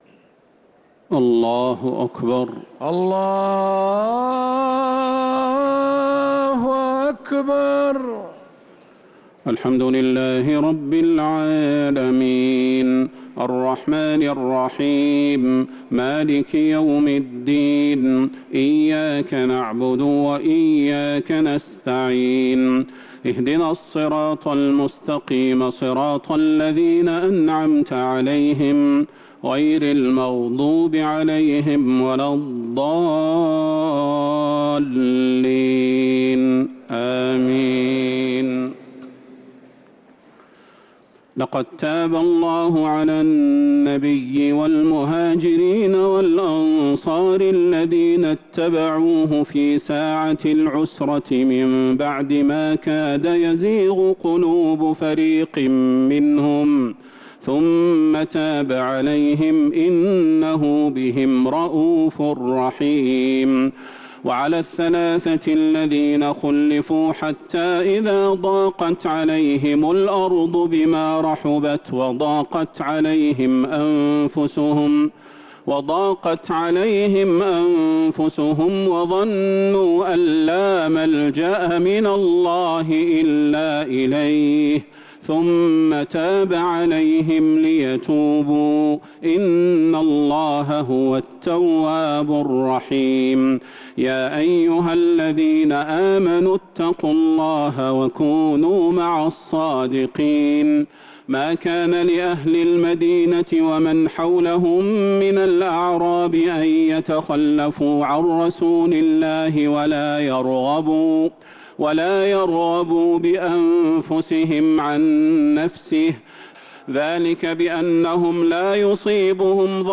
تراويح ليلة 14 رمضان 1447هـ من سورتي التوبة (117_129) و يونس (1-25) | Taraweeh 14th niqht Surat At-Tawba and Yunus 1447H > تراويح الحرم النبوي عام 1447 🕌 > التراويح - تلاوات الحرمين